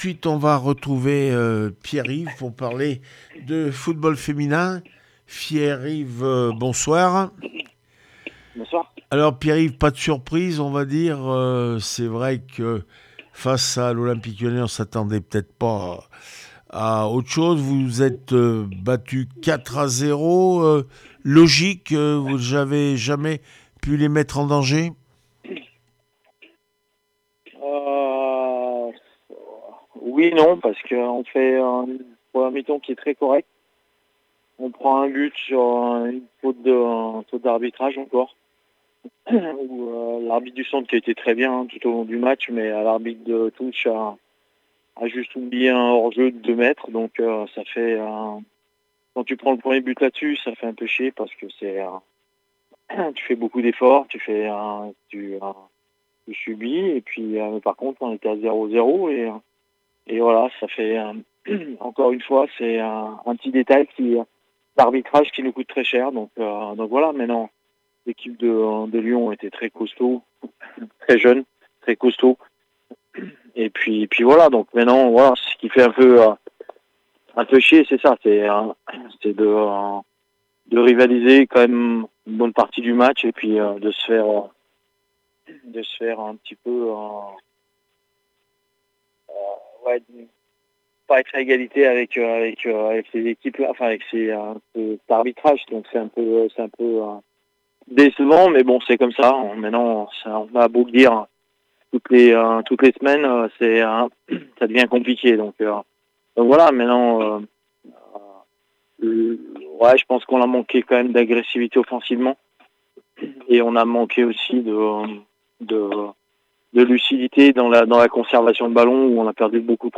17 février 2026   1 - Sport, 1 - Vos interviews
D3F foot le puy foot 43 0-4 olympique lyonnais réaction après match